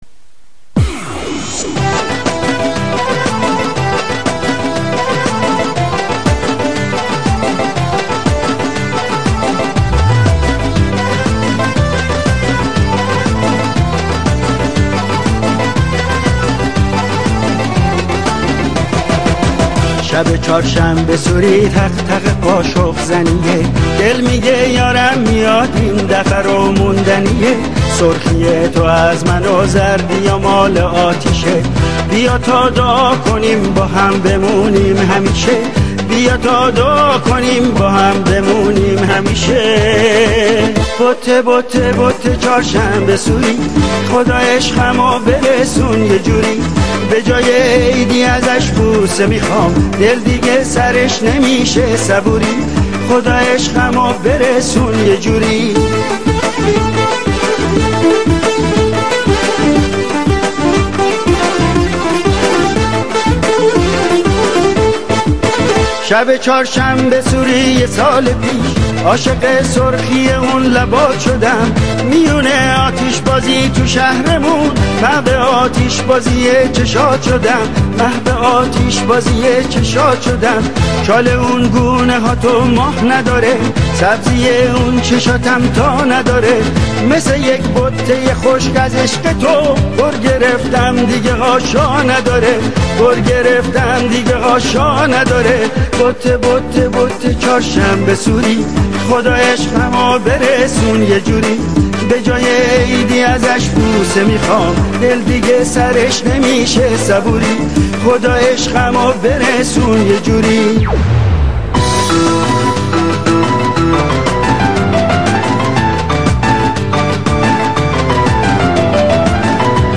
آهنگ چهارشنبه سوری قدیمی دهه ۵۰ و ۶۰